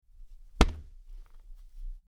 Body Hit
Body_hit.mp3